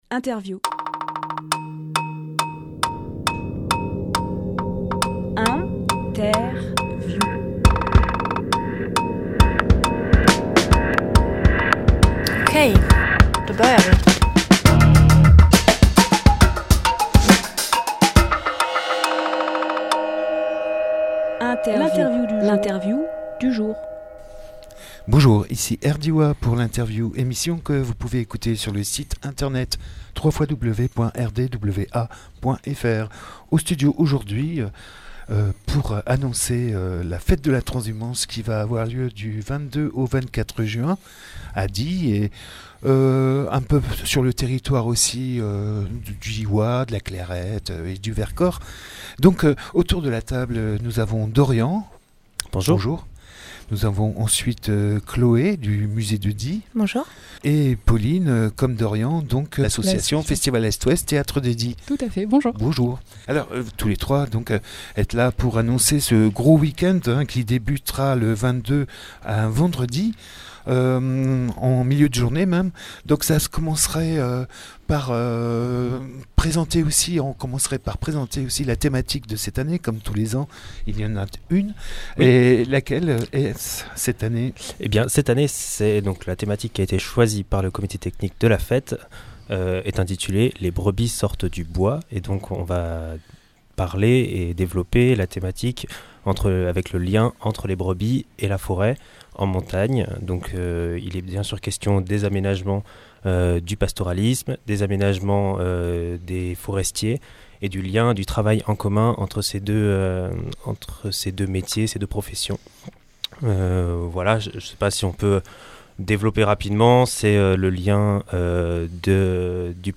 Emission - Interview Fête de la Transhumance Publié le 8 juin 2018 Partager sur…
Lieu : Studio RDWA